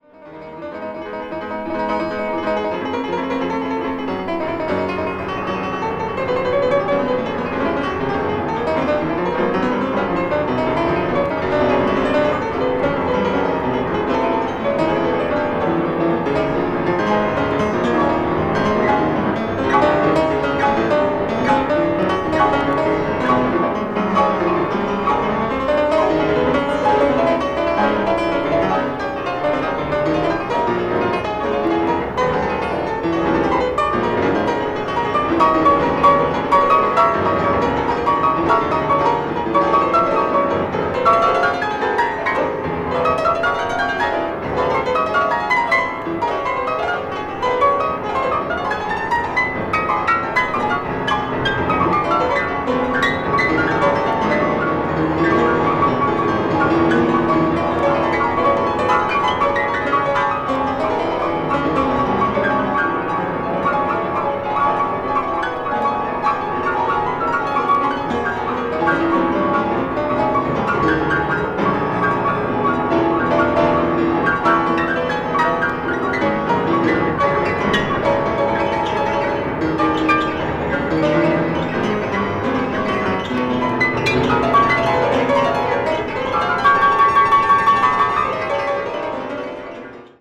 piano duo album
avant-jazz   free improvisation   free jazz   piano duo